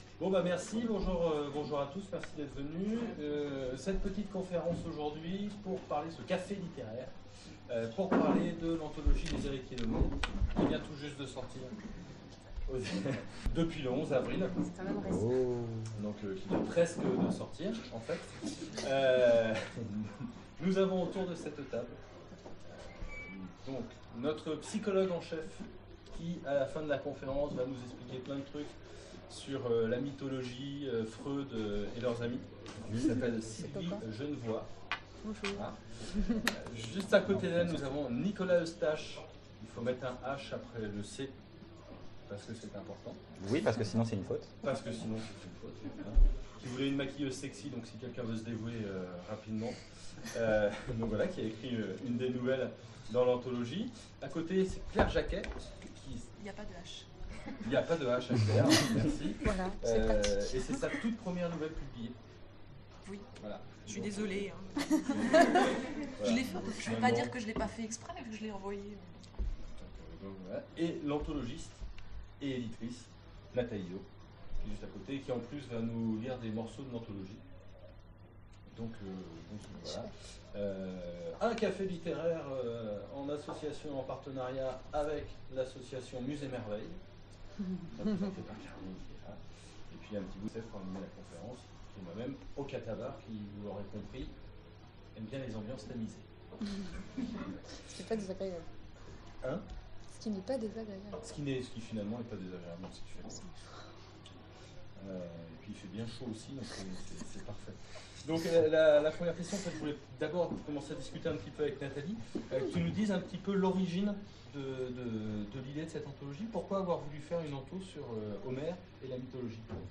Conférence Les Héritiers d'Homère